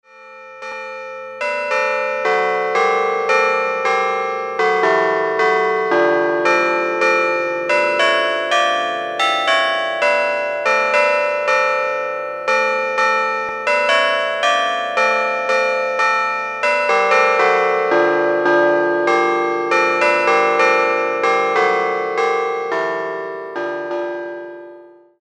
WE GATHER TOGETHER – ENGLISH BELLS
We-Gather-Together-English-Bells.mp3